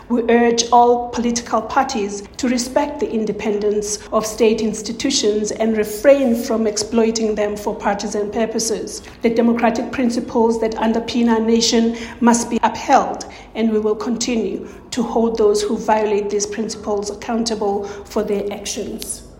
The DA’s Bridget Masango says the party stands firm in its commitment to ethical, honest, and appropriate political conduct: